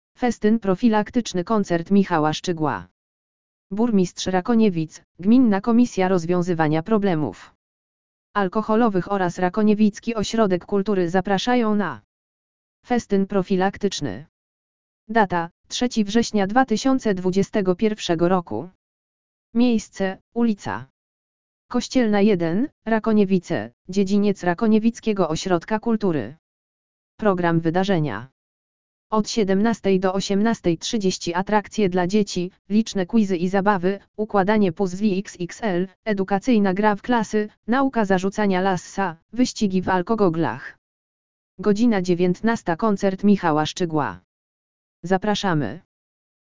AUDIO LEKTOR